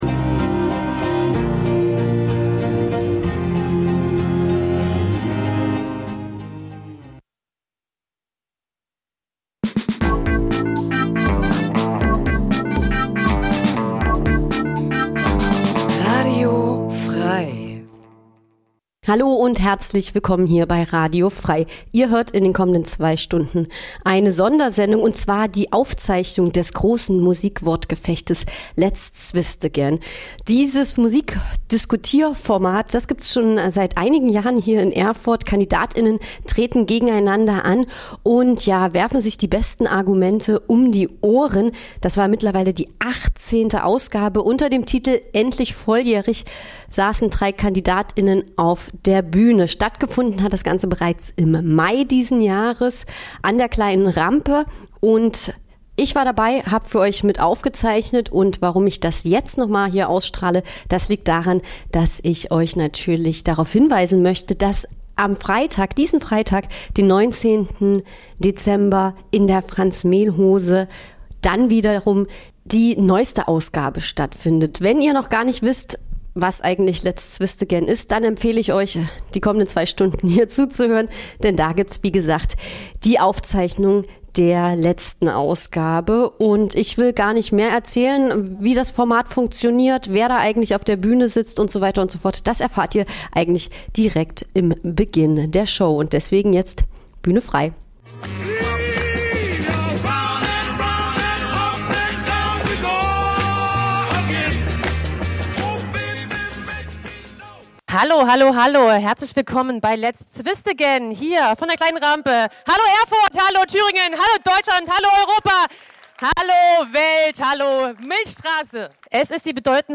Aufzeichnung vom 24. Mai 2025 in der Kleinen Rampe Erfurt.